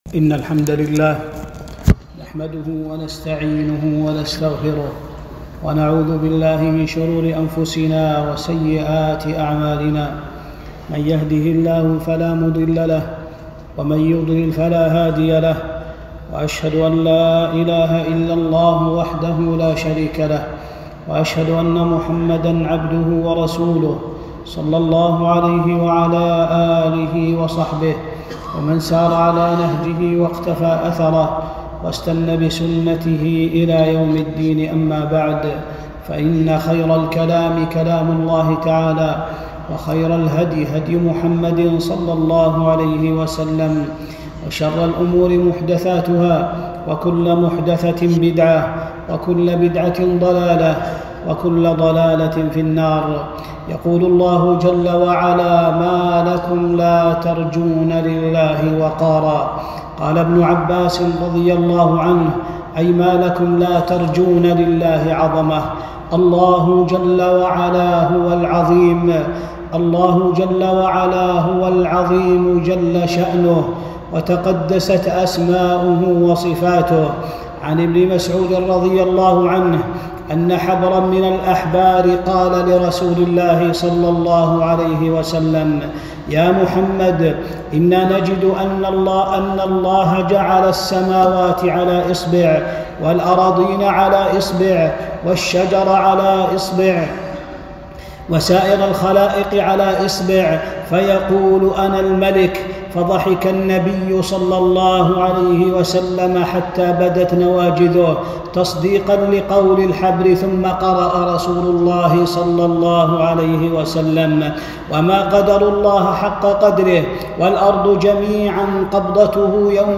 خطبة - عظمة الله والافتقار إليه